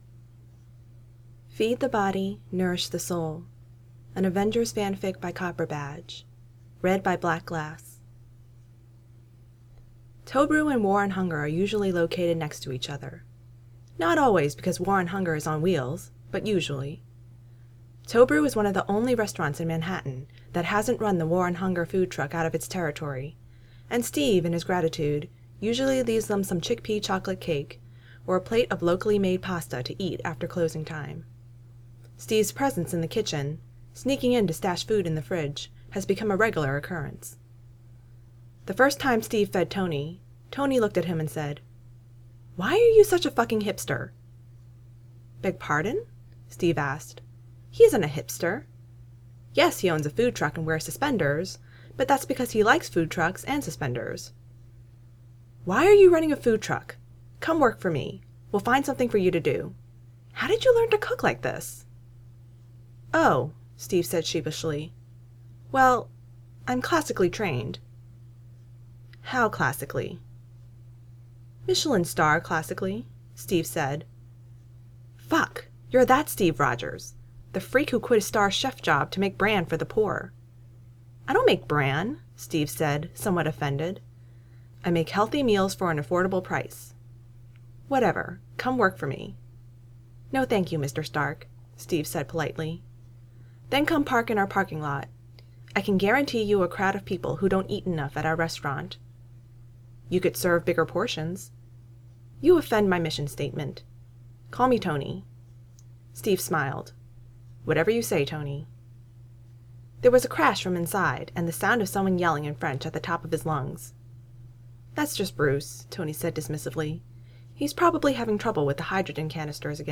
Feed the Body, Nourish the Soul [Podfic]